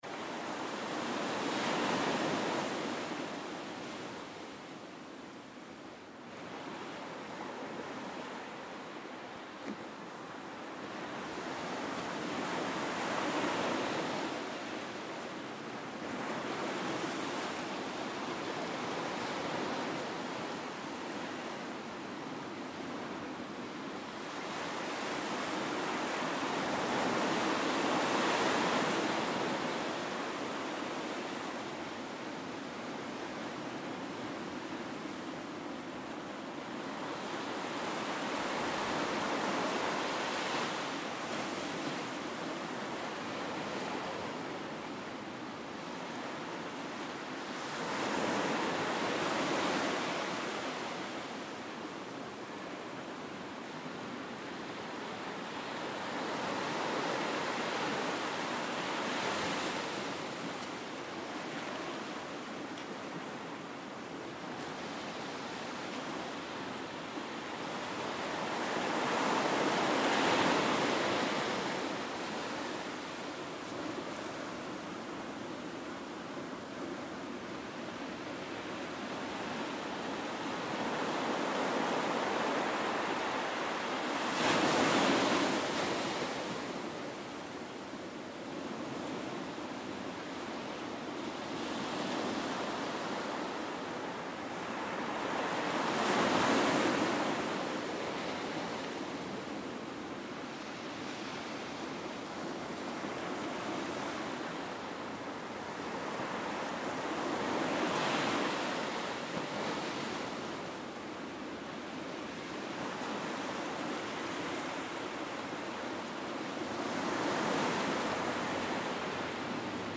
Ambiences Stereo Galicia " mar 2 4m
描述：距离海边4米h4n X/Y